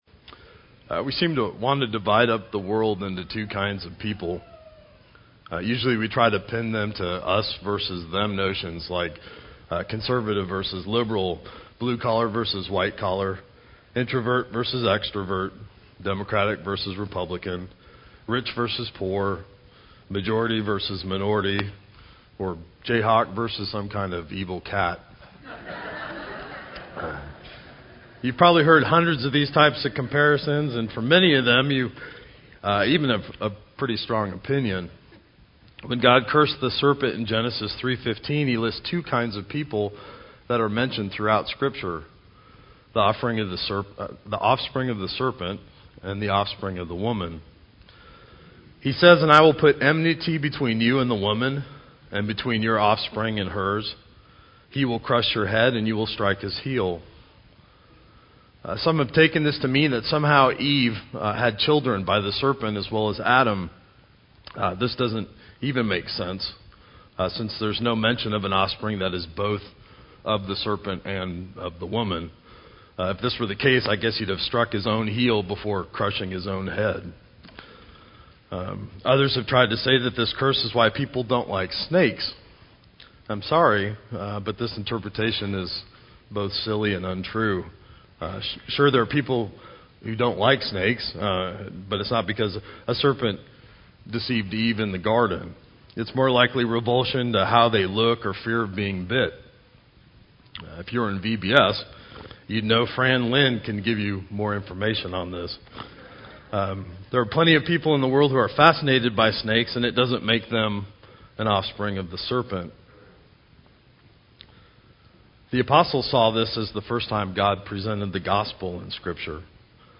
The second example refers to the scripture account of Cain and Abel. I preached a sermon on this once which you are welcomed to download here if you want to learn more about it.